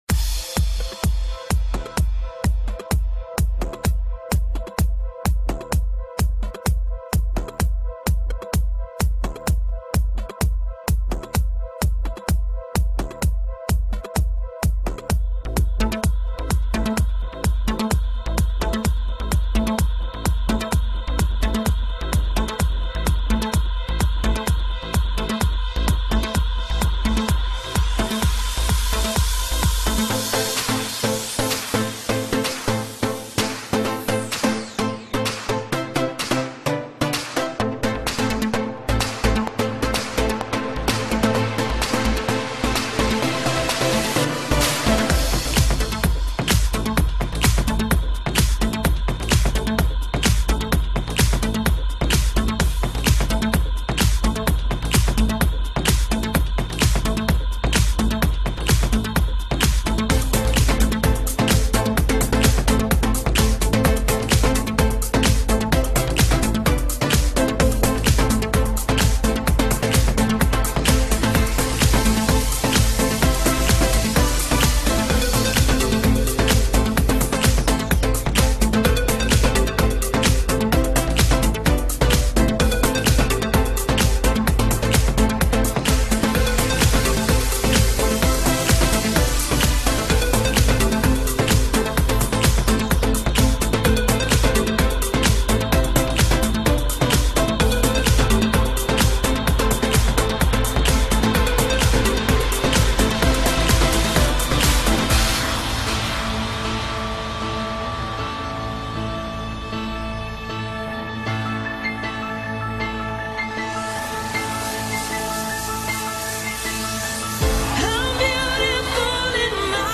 Club | [